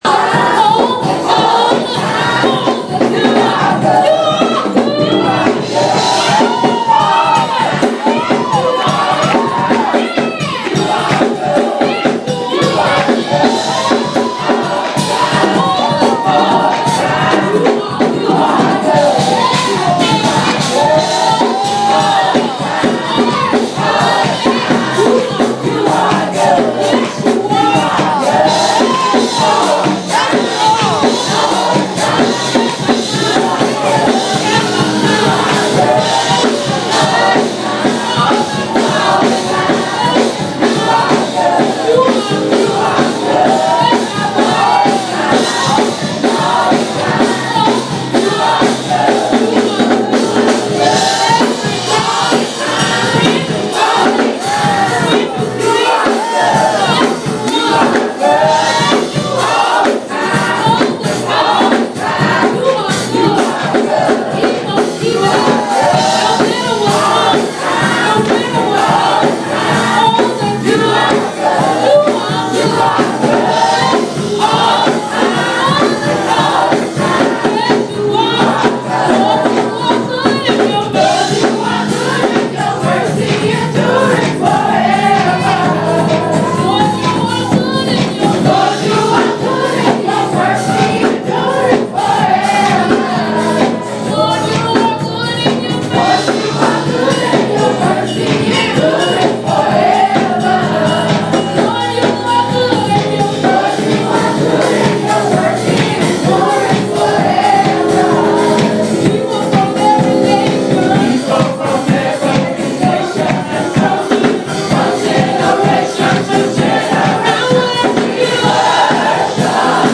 So no restaurant review today, but as a treat, I leave you with a snippet of music from the service. I caught them at the beginning of their musical breakdown, so give it until 1:20 to get back into the song.